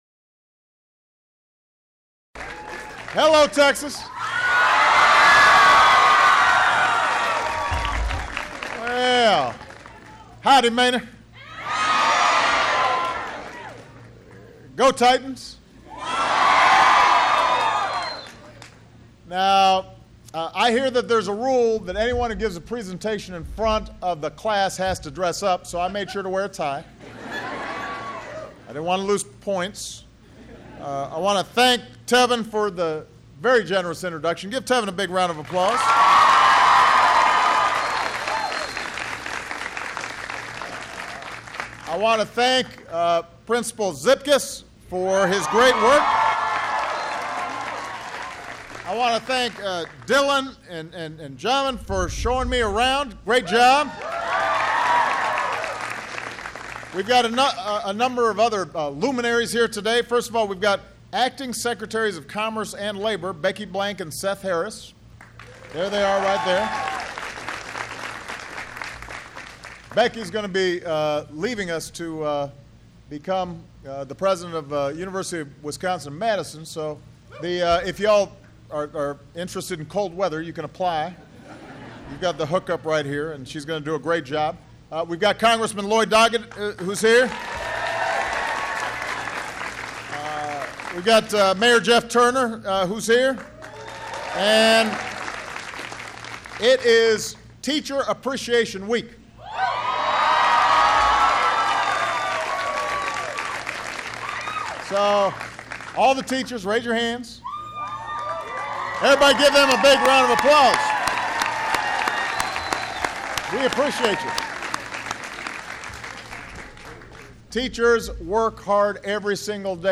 U.S. President Barack Obama speaks to students at Manor New Tech High School near Austin, TX